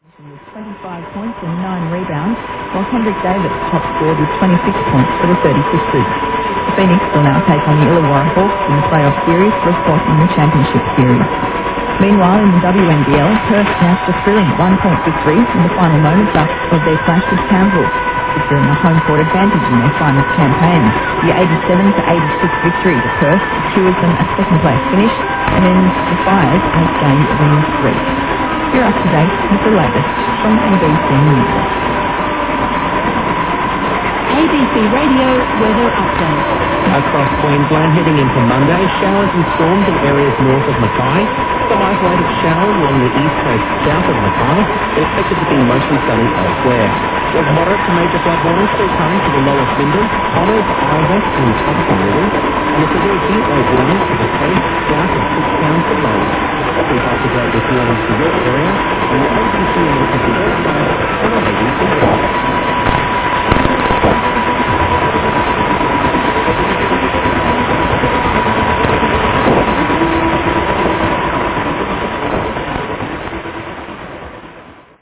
17日02時台、ABC-4QDでABC News終了後、30秒間のABC Radio Weather Updateが聞こえました。
バックではRadio Fardaと思われる音楽が聞こえおりました。
<受信地：東京都江東区新砂 東京湾荒川河口 RX:ICF-SW7600GR ANT:Built-in bar>